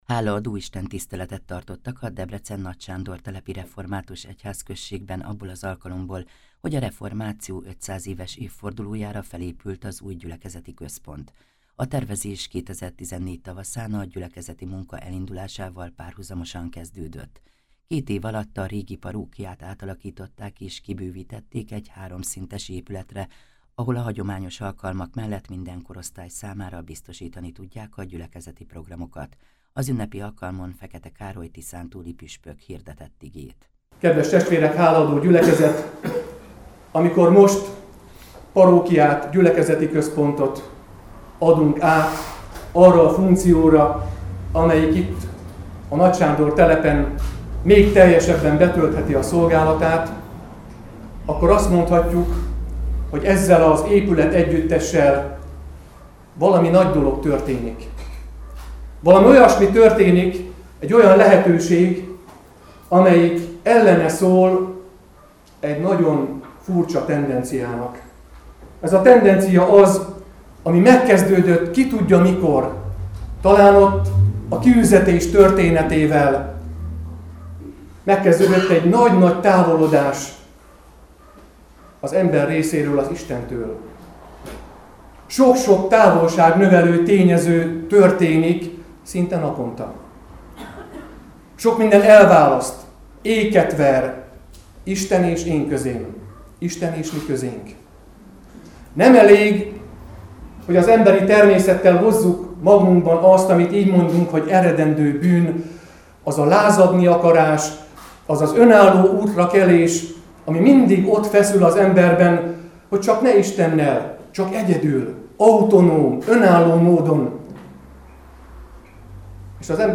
Az ünnepi alkalmon Fekete Károly tiszántúli püspök hirdette az igét Jak 4, 7-8 alapján.